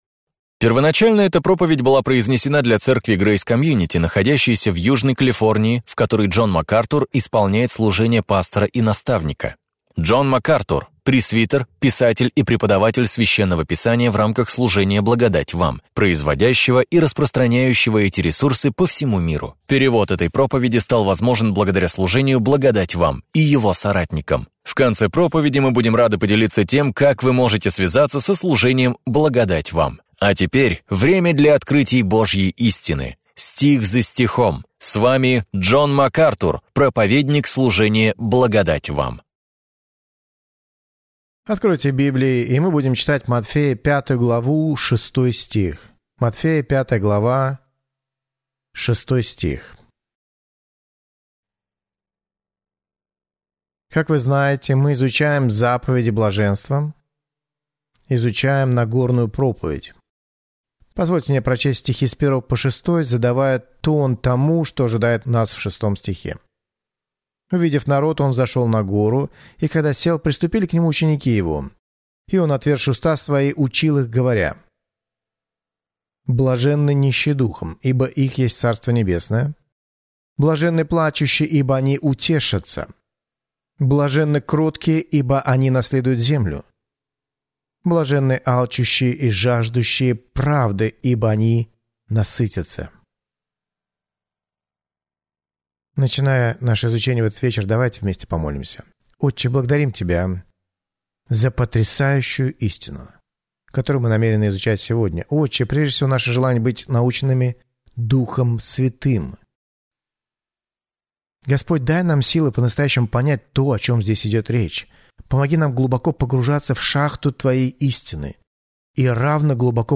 В своей проповеди «Заповеди блаженства» Джон Макартур делает обзор утверждений Христа – заповедей блаженства, исследующих моральные, этические и духовные предписания, которыми руководствуются Божьи люди.